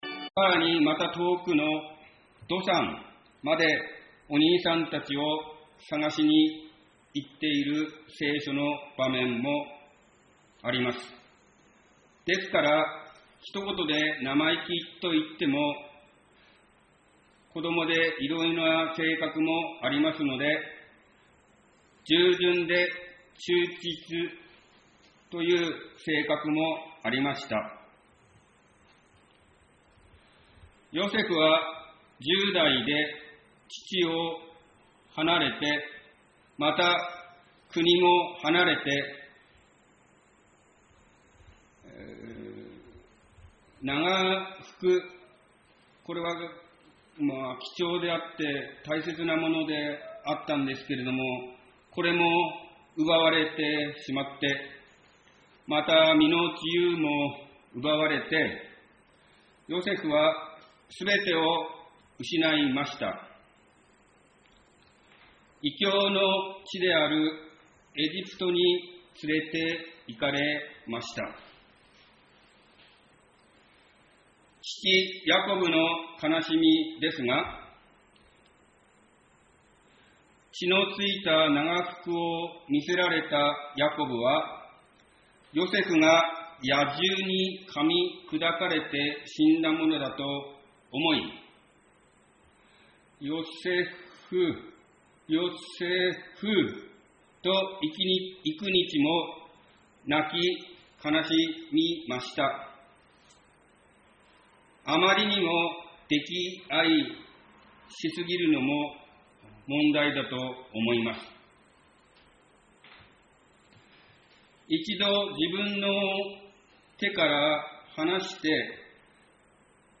ヨセフ物語奨励(途中から) 宇都宮教会 礼拝説教